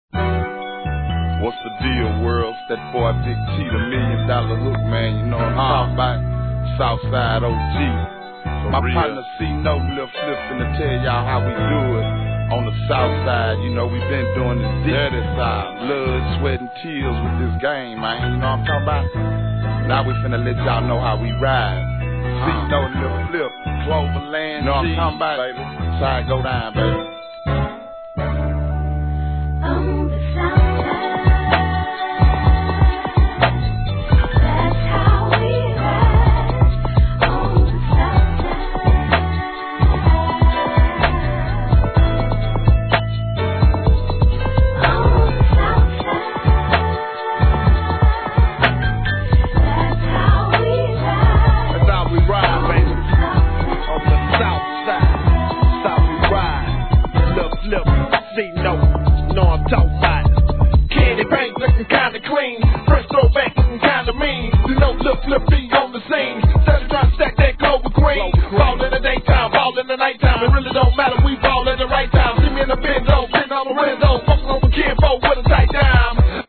G-RAP/WEST COAST/SOUTH
王道の哀愁メロ〜傑作!!